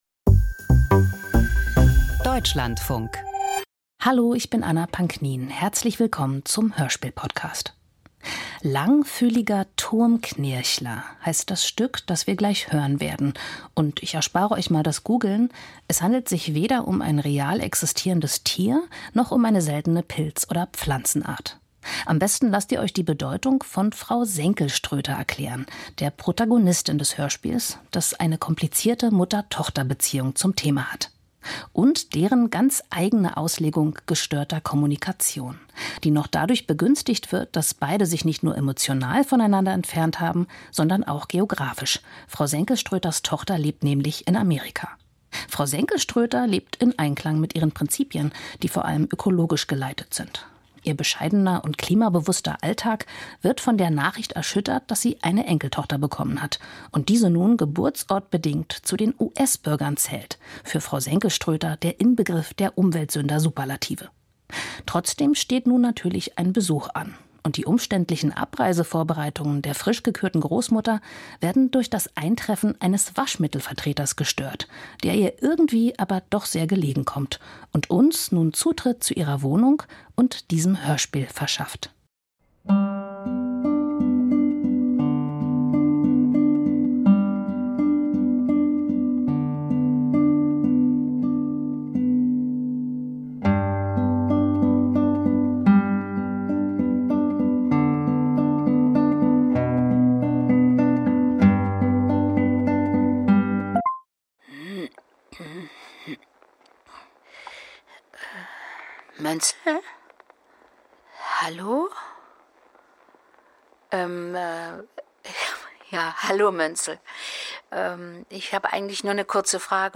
the weird & the eerie - Klangkunst: Dialog mit KI-Doppelgängern – Hörspiel – Lyssna här